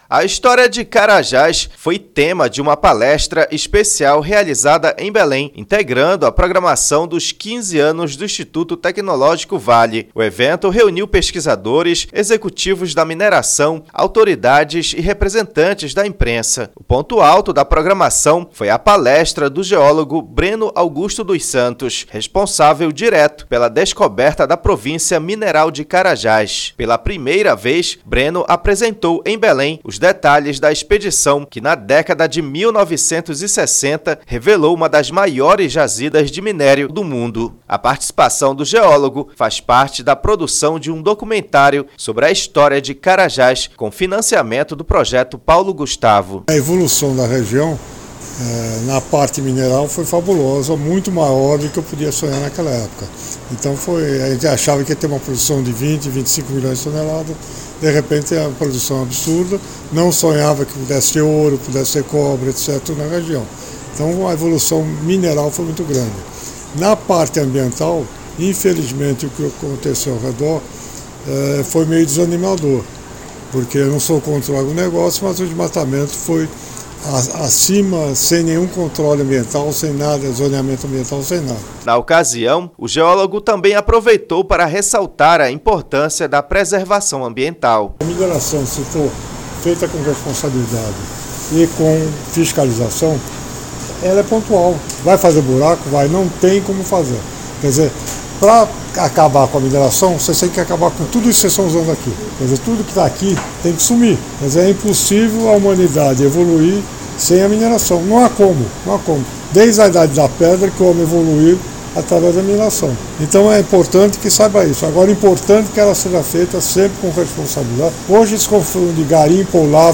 História de Carajás é tema de palestra histórica em Belém, como parte dos 15 anos do Instituto Tecnológico Vale
0---PALESTRA-GELOGO-CARAJS.mp3